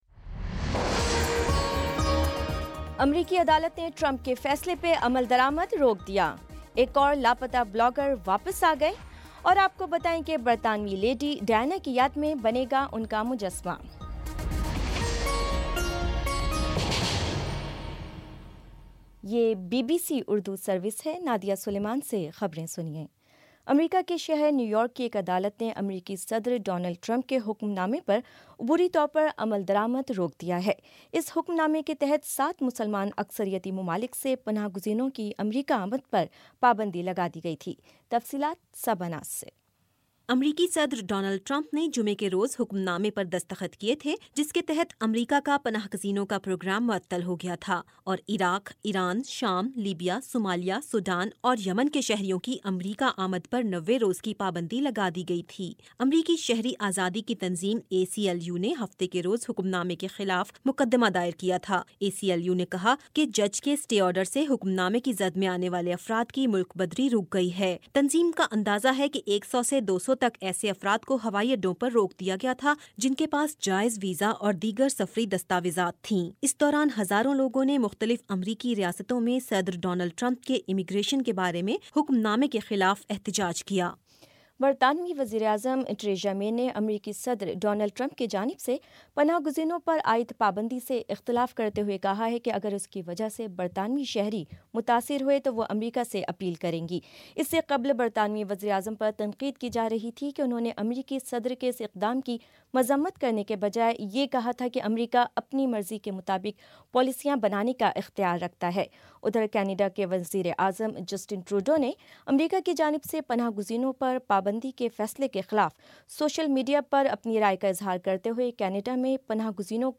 جنوری 29 : شام پانچ بجے کا نیوز بُلیٹن